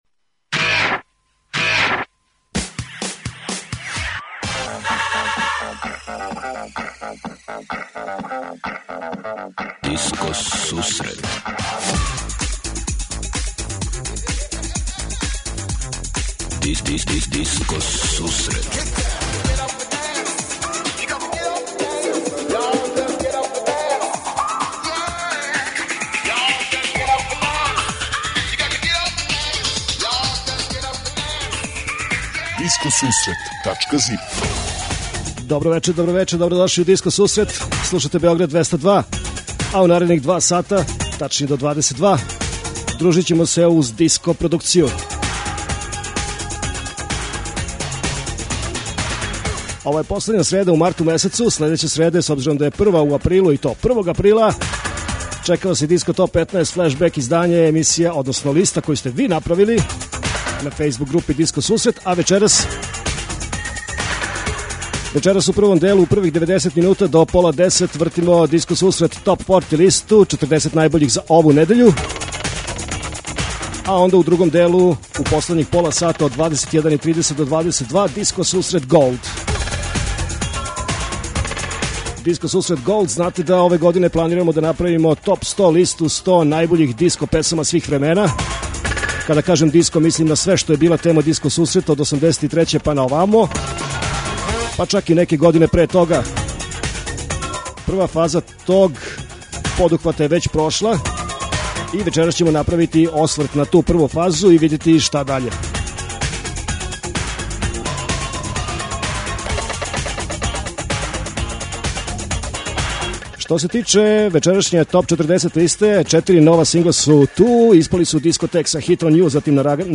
Диско сусрет је емисија посвећена најновијој и оригиналној диско музици у широком смислу, укључујући све стилске утицаје других музичких праваца - фанк, соул, РнБ, итало-диско, денс, поп. Непосредан контакт са слушаоцима уз пуно позитивне енергије је основа на којој ова емисија гради забаву сваке среде.